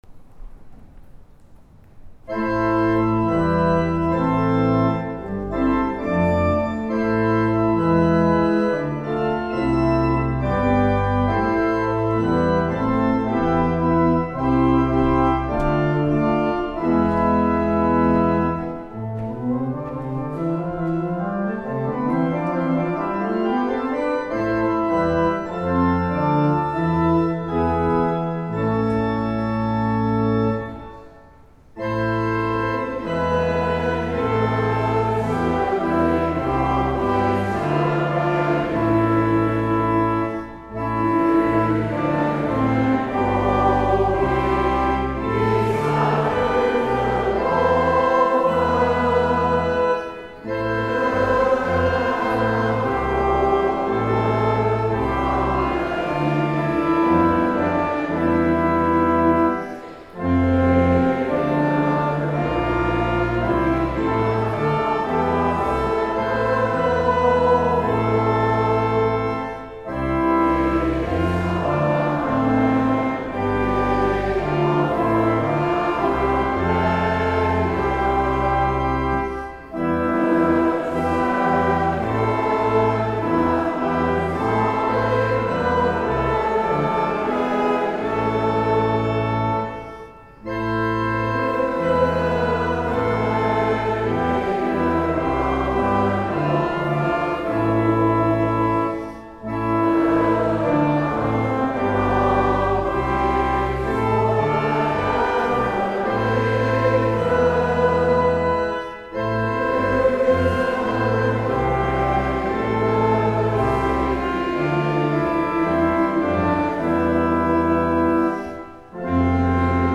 Bij de live-opnamen met samenzang is de opstelling van de microfoons niet altijd optimaal. In GKO maar ook in KKV stond de microfoon op de orgelgallerij, waardoor het orgel in de opname enigszins overheerst.
Psalm 016 live.mp3